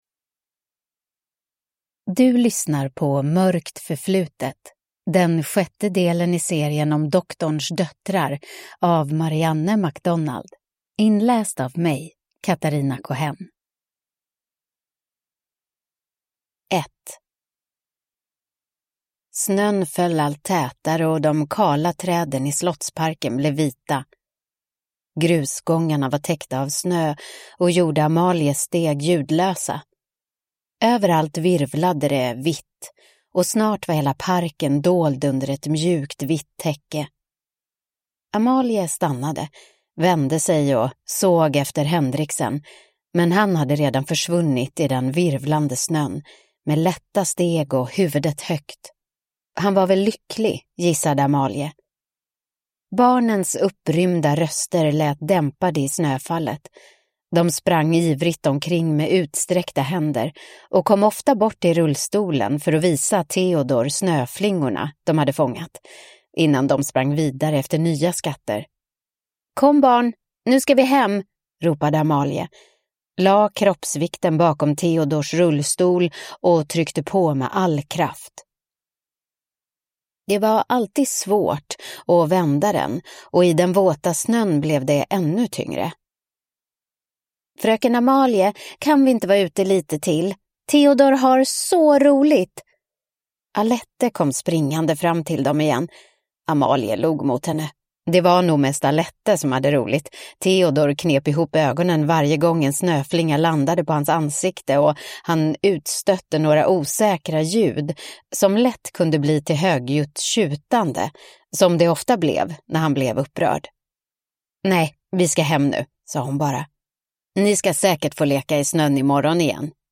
Mörkt förflutet (ljudbok) av Marianne MacDonald